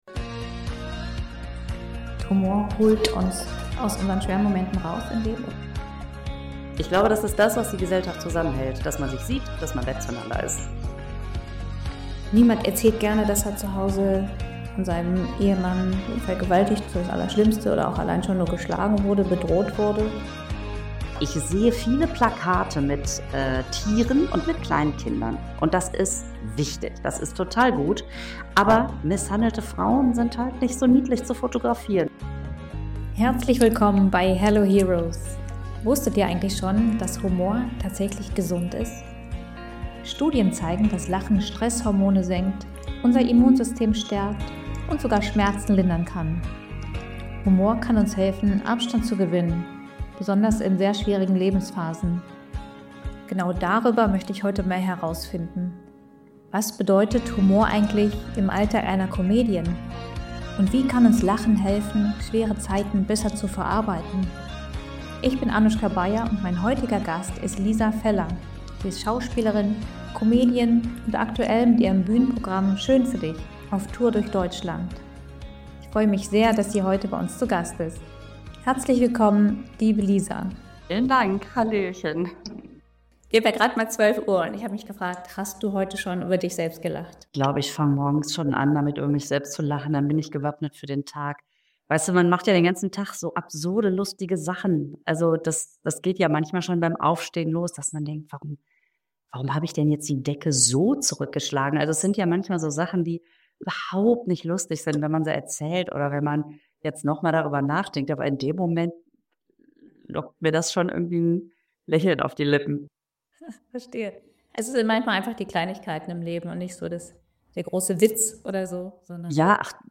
Ich danke Lisa für dieses schöne und unterhaltsame Gespräch!